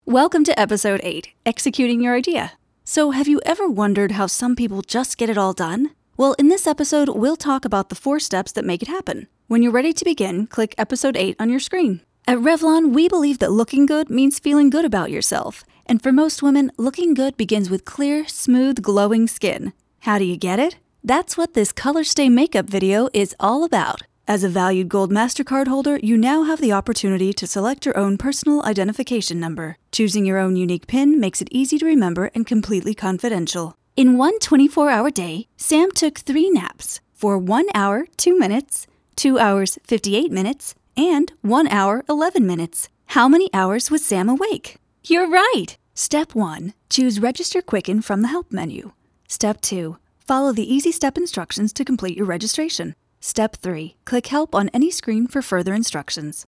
Fresh/hip, clear, bright, conversational ..and sassy/wry when it's called for.
englisch (us)
Sprechprobe: eLearning (Muttersprache):